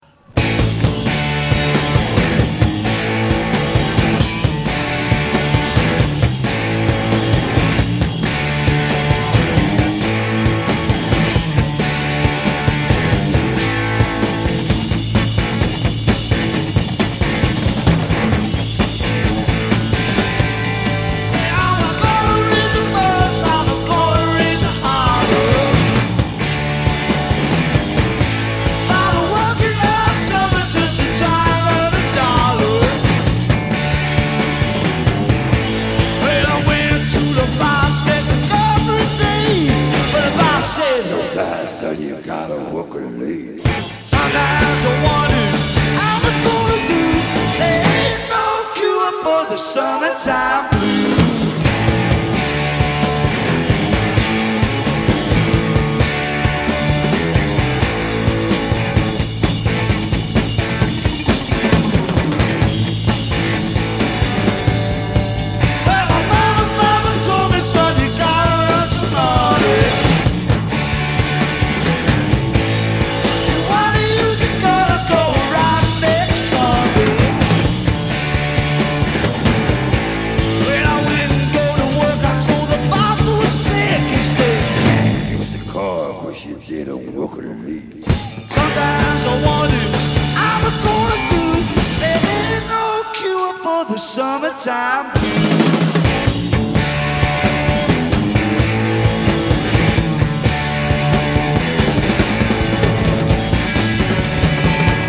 Leeds et Hull.